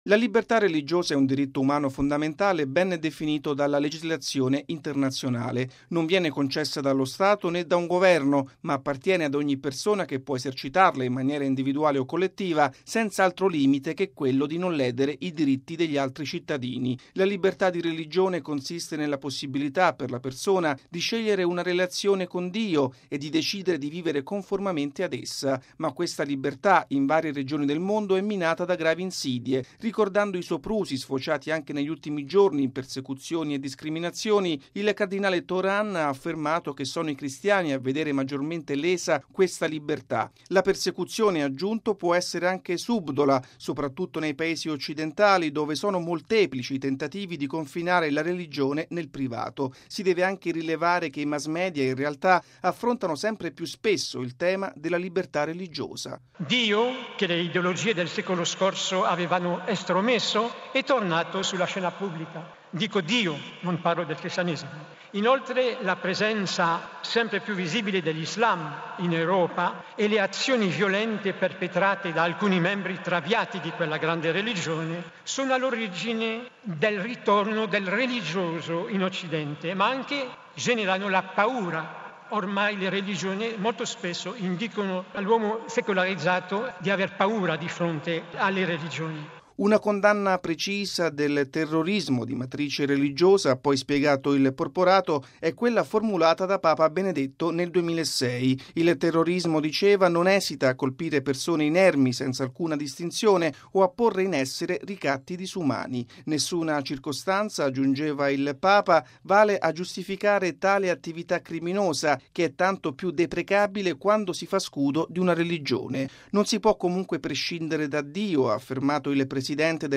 ◊   La cruciale tematica della libertà religiosa, esplorata nella sua dimensione di diritto umano fondamentale minacciato da attacchi non solo cruenti ma anche subdoli, è stata al centro dell’intervento, ieri al Meeting di Rimini, del cardinale Jean-Louis Tauran, presidente del Pontificio Consiglio per il Dialogo Interreligioso.